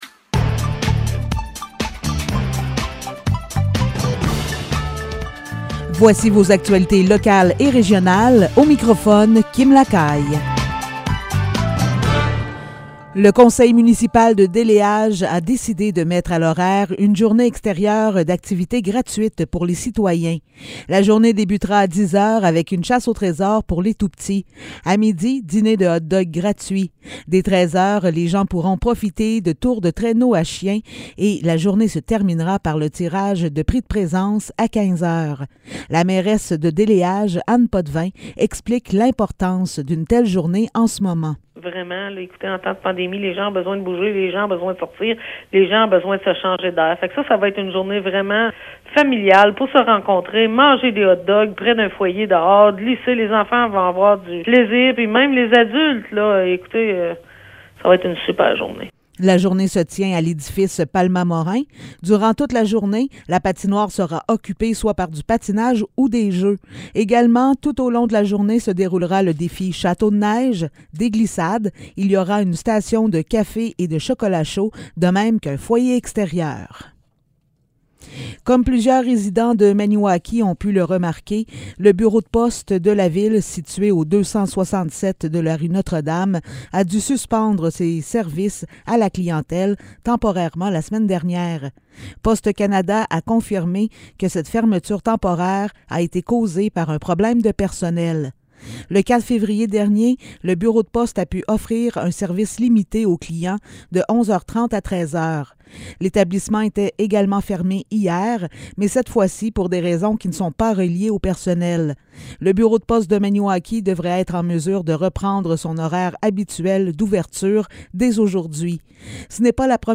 Nouvelles locales - 10 février 2022 - 15 h